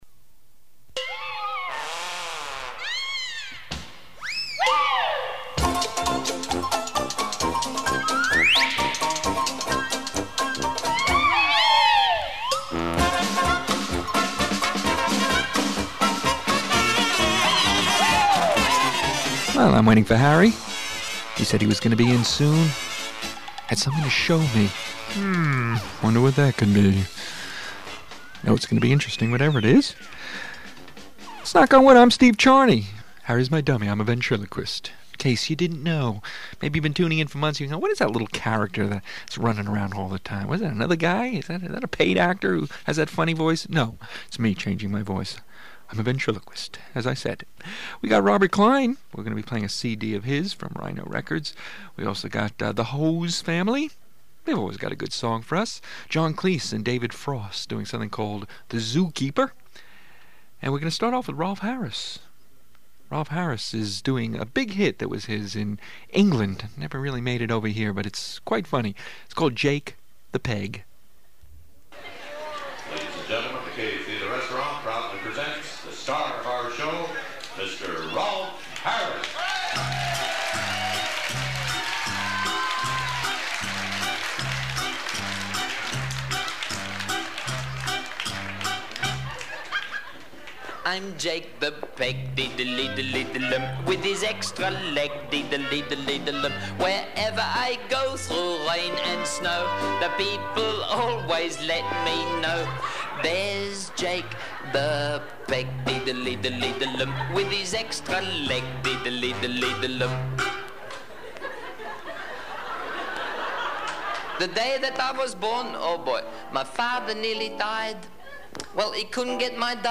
Comedy Show